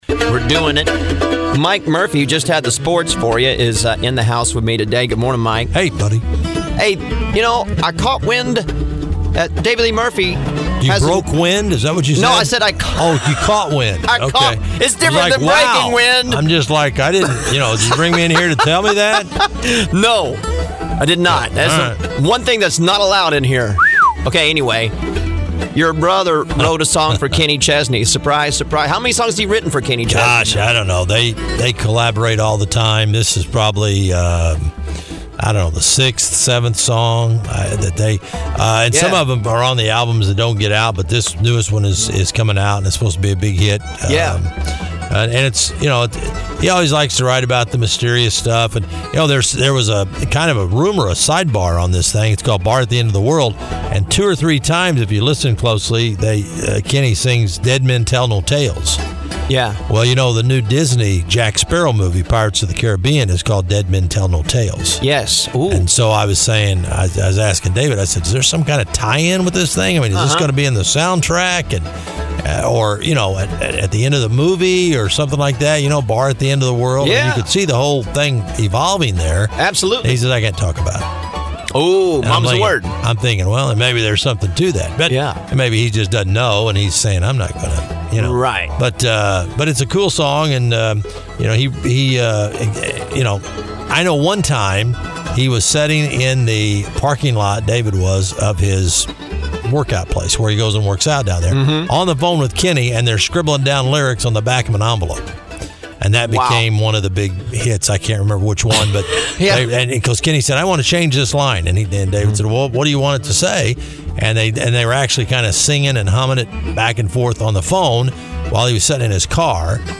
in the studio for an update!